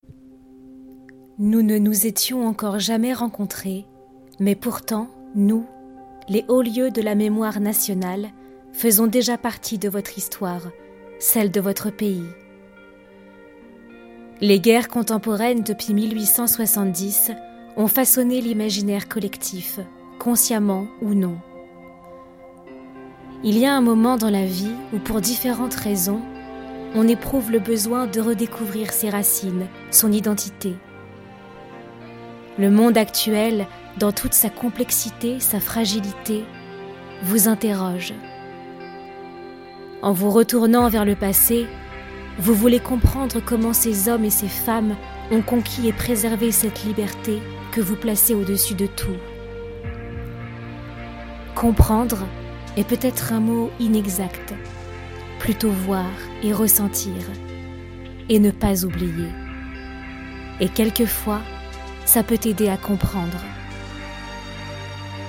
Maquette - Les Hauts lieux de la Mémoire Nationale (narration)